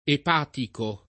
epatico [ ep # tiko ]